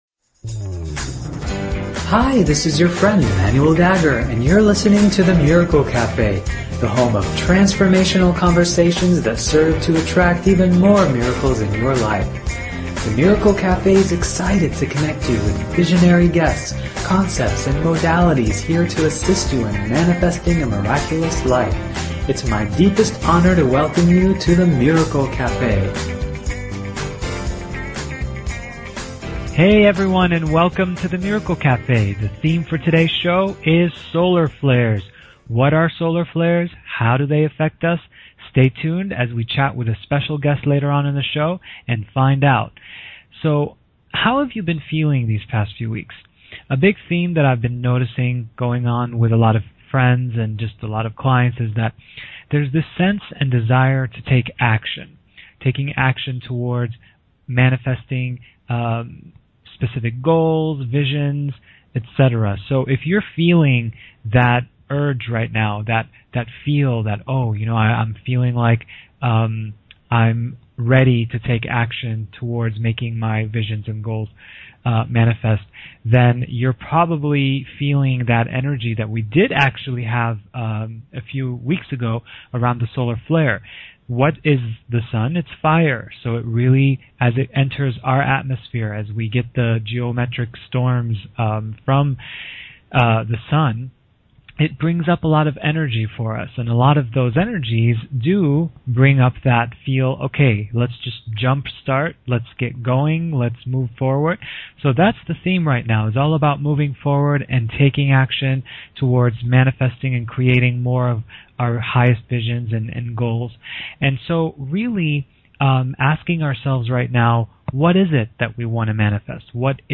Talk Show Episode, Audio Podcast, The_Miracle_Cafe and Courtesy of BBS Radio on , show guests , about , categorized as